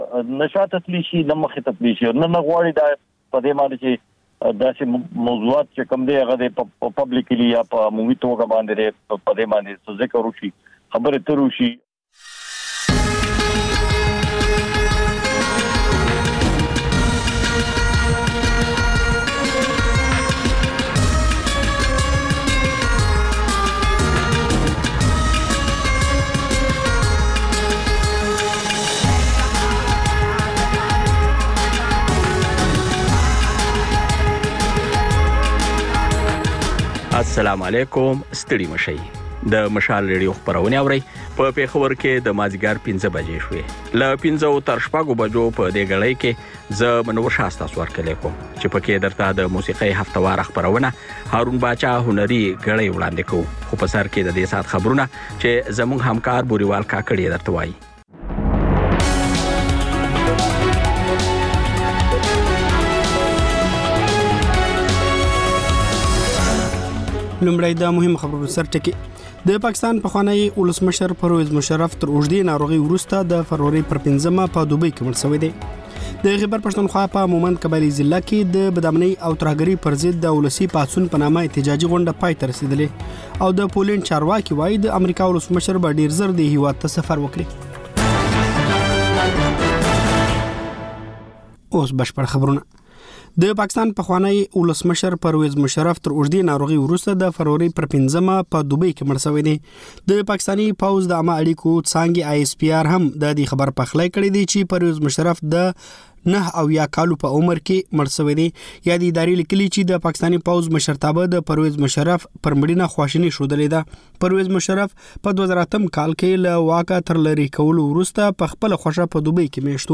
د مشال راډیو ماښامنۍ خپرونه. د خپرونې پیل له خبرونو کېږي، بیا ورپسې رپورټونه خپرېږي.
ځېنې ورځې دا مازیګرنۍ خپرونه مو یوې ژوندۍ اوونیزې خپرونې ته ځانګړې کړې وي چې تر خبرونو سمدستي وروسته خپرېږي.